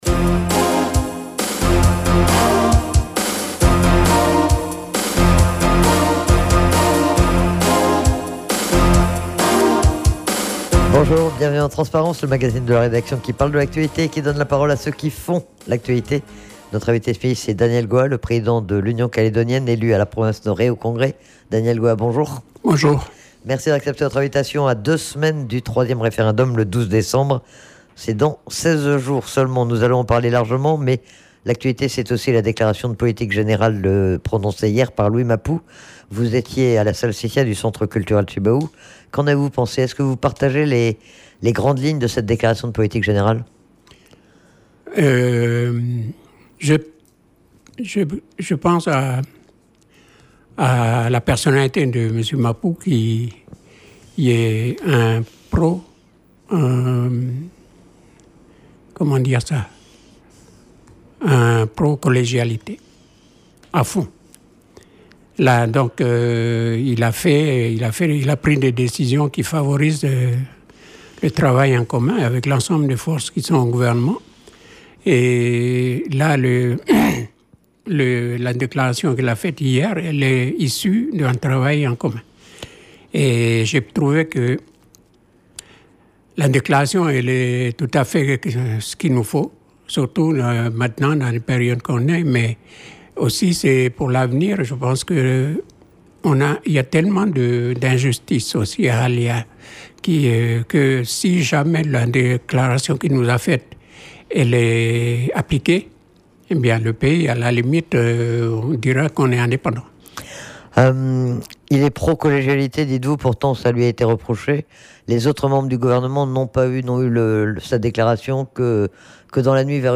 Daniel Goa est interrogé sur le contexte politique actuel, à un peu plus de deux semaines du 3ème référendum pour lequel les indépendantistes appellent à la non-participation, mais aussi sur l'après 12 décembre.